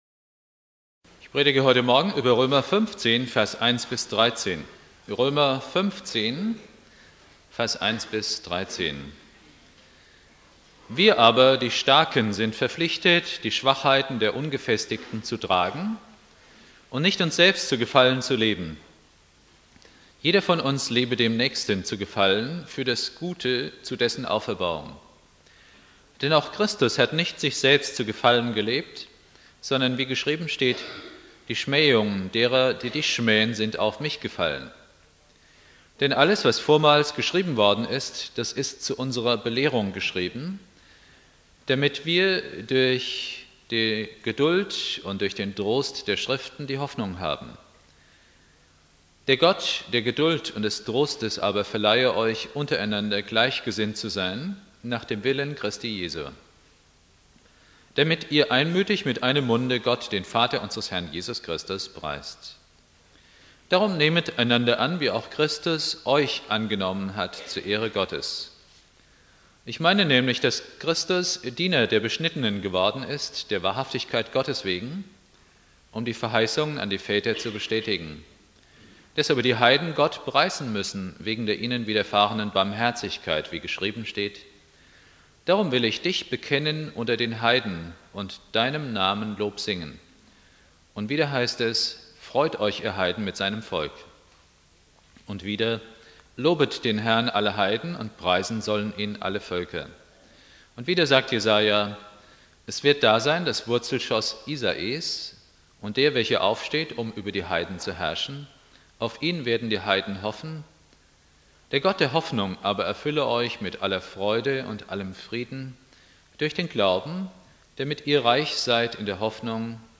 Predigt
3.Advent Prediger